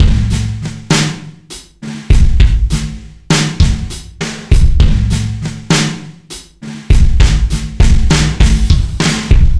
jazz drunk 100bpm 01.wav